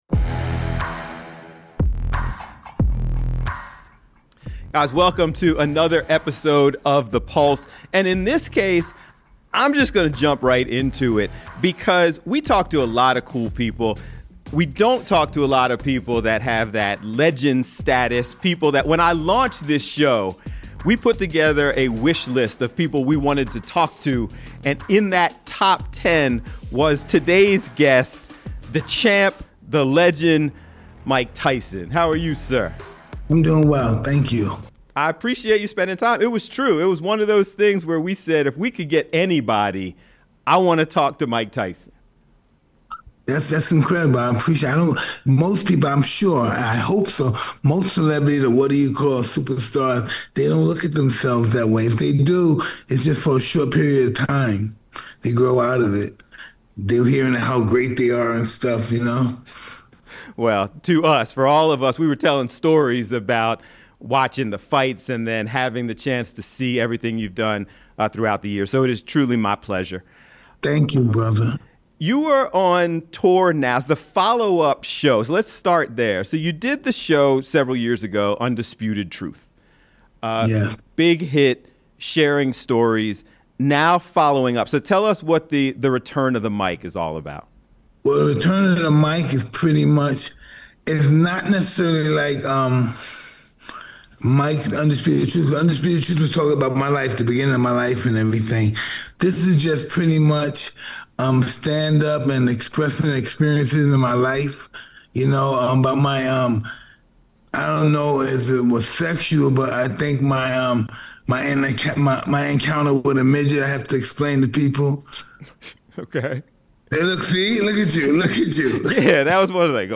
The one and only Mike Tyson opens up about his journey, overcoming personal struggles, and his new live show "Return of the Mike" in a deeply touching and personal interview